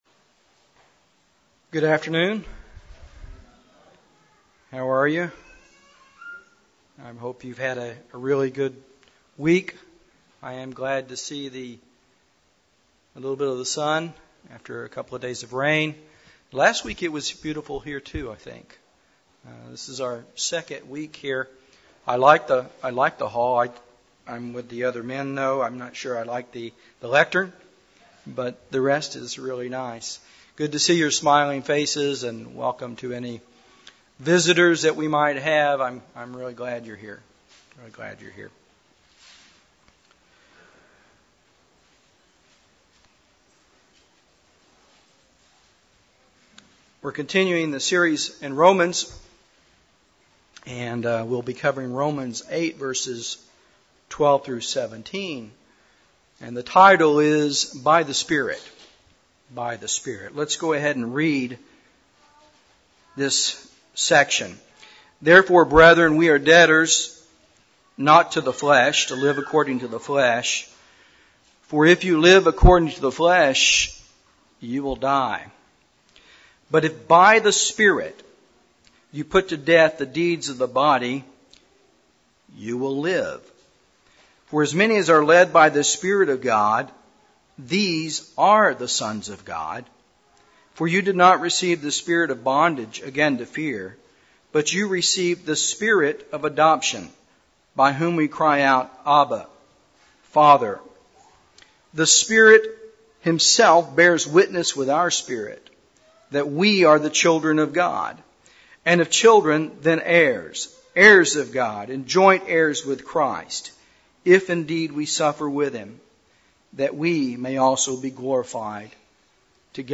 This sermon goes to the heart of how sin is conquered and provides practical steps for Christians.